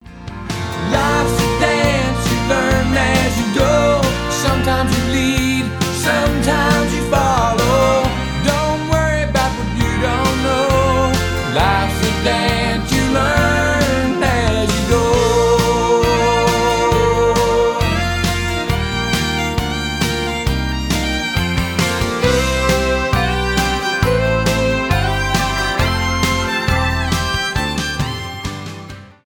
скрипка
танцевальные , позитивные
кантри